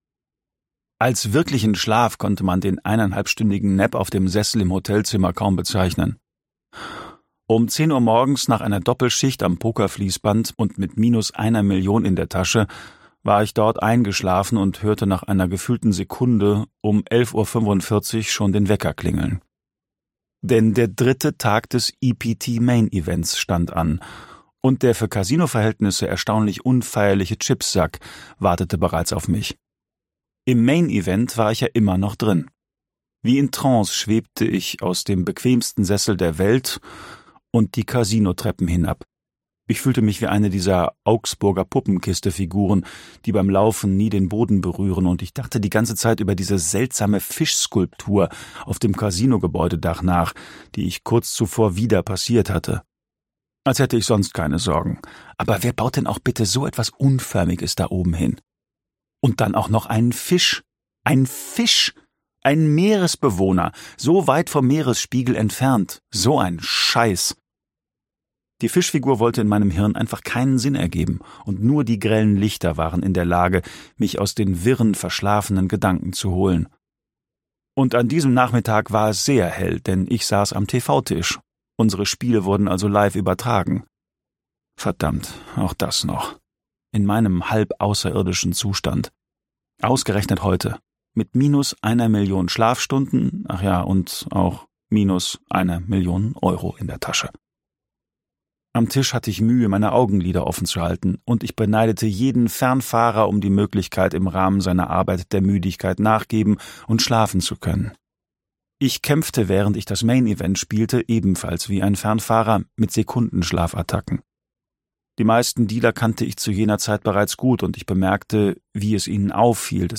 2025 | Ungekürzte Lesung